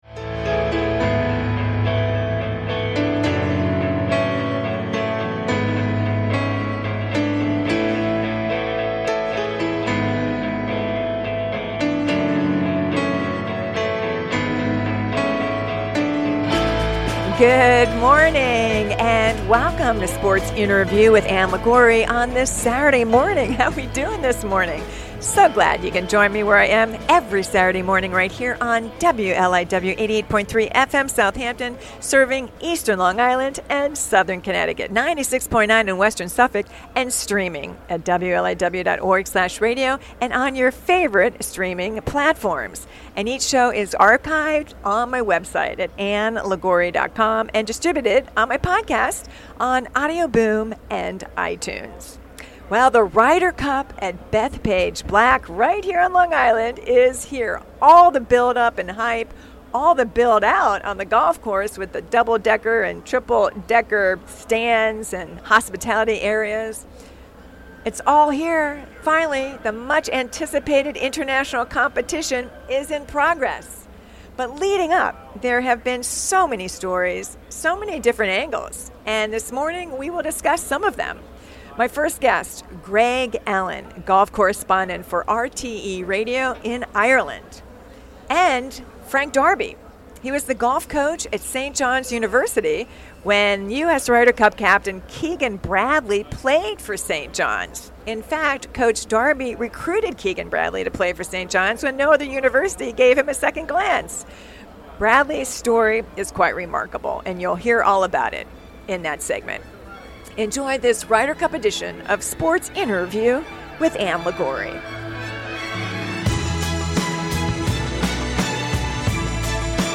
broadcasts from the Ryder Cup at Bethpage Black